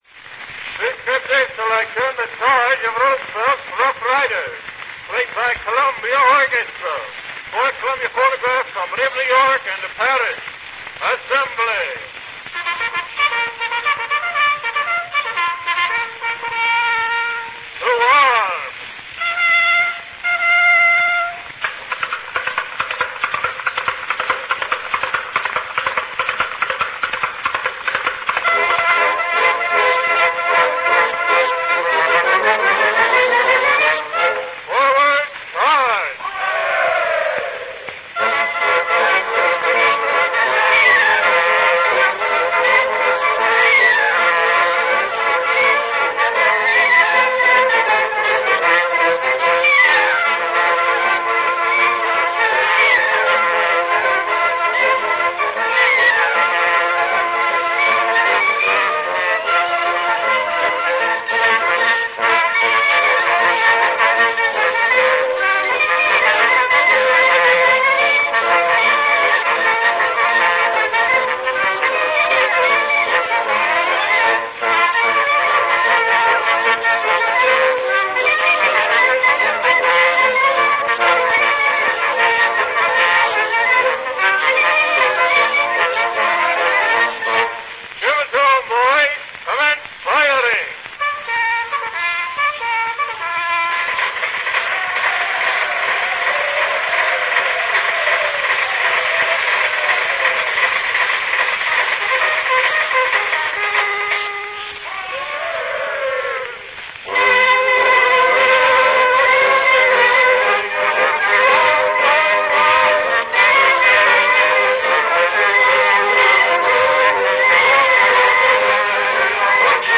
RealAudio file from a  wax cylinder recording